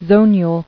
[zon·ule]